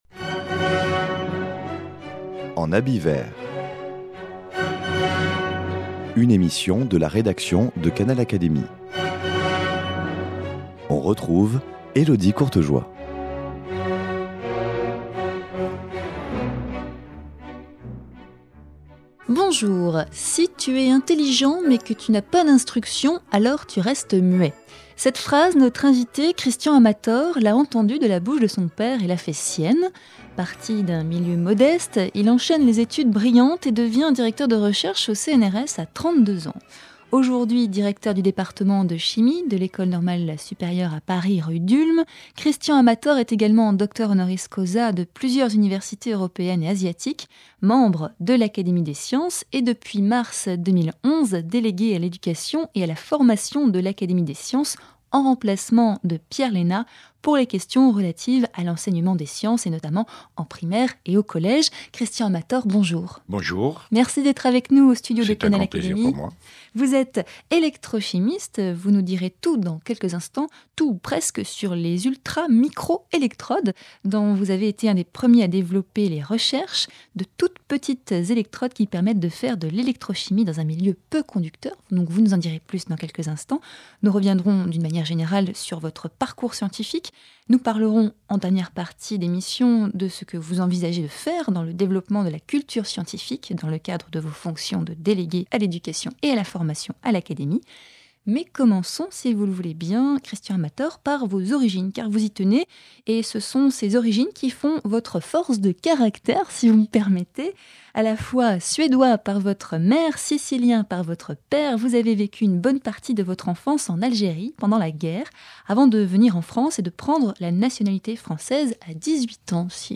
Au cours de cette émission, notre invité nous livre des bribes de son enfance en Algérie pendant la guerre, le soutien silencieux de ses parents dans ses brillantes études, et ses travaux actuels révolutionnaires.